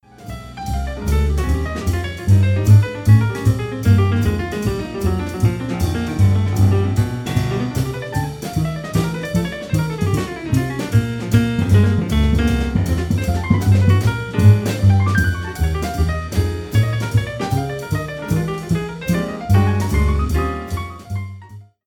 en concert